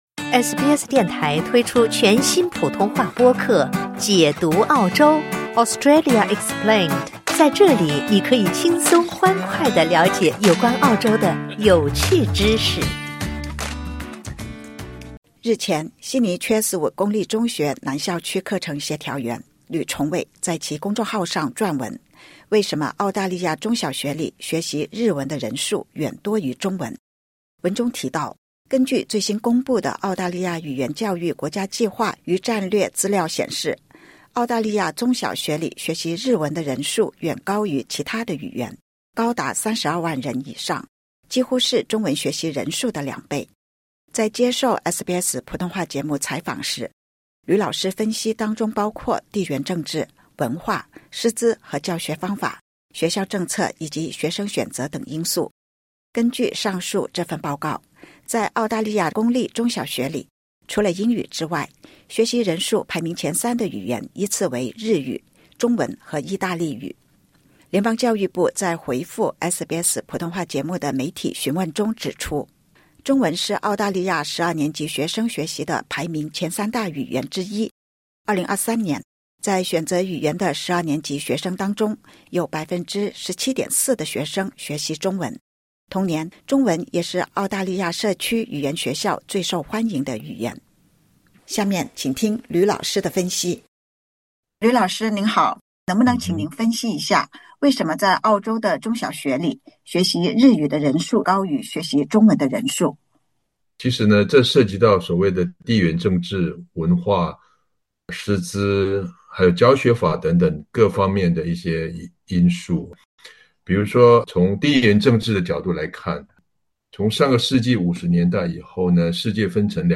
接受SBS普通话节目采访时